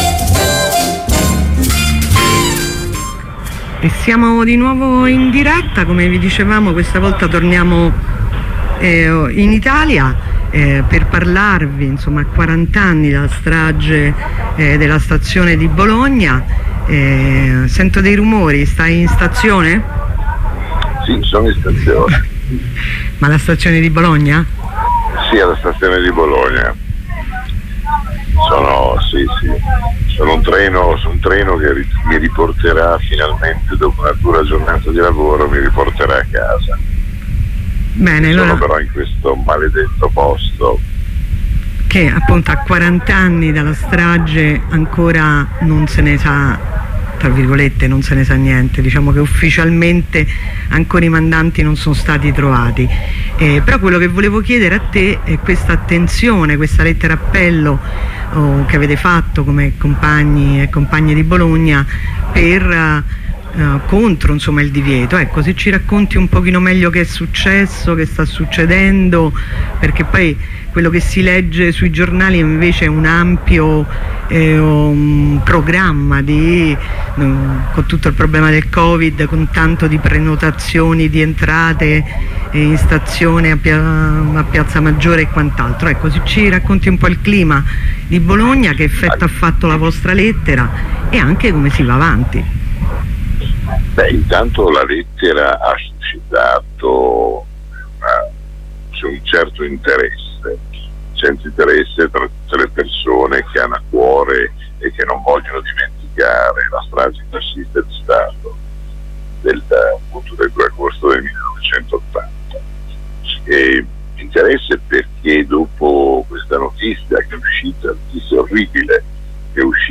Ne parliamo con un compagno bolognese